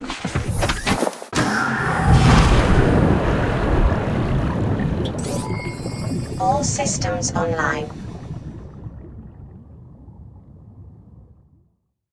Undock_seamoth_cyclops.ogg